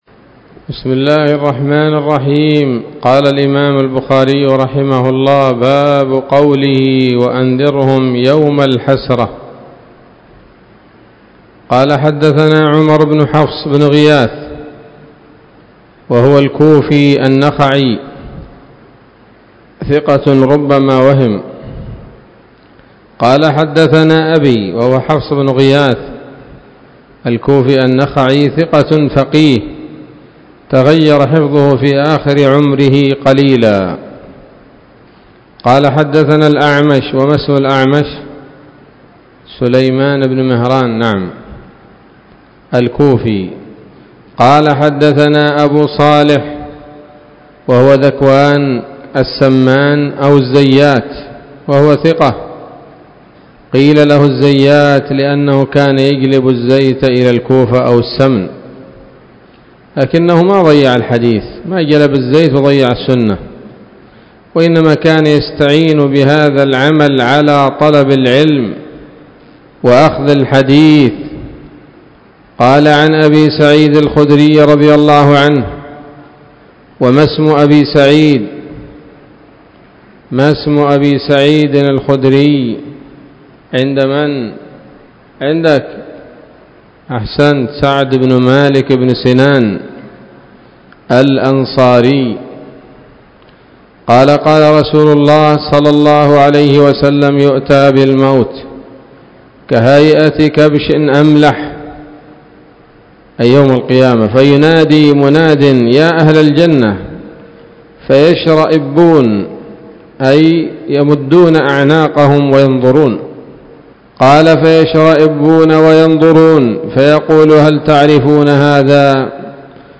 الدرس السابع والستون بعد المائة من كتاب التفسير من صحيح الإمام البخاري